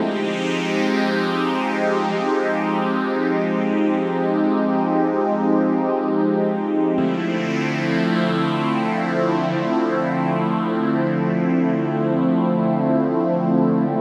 Pad_137_F.wav